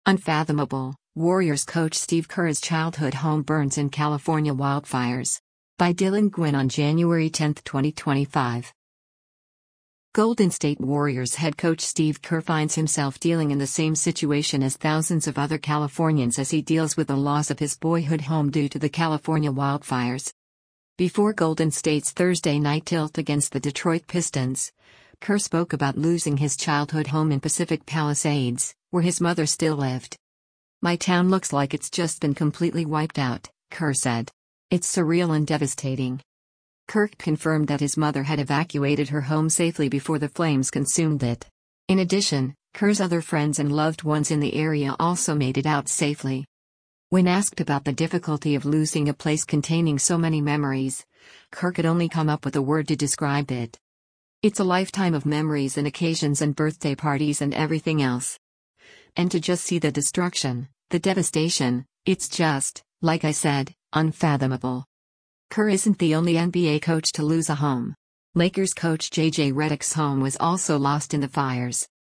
Before Golden State’s Thursday night tilt against the Detroit Pistons, Kerr spoke about losing his childhood home in Pacific Palisades, where his mother still lived.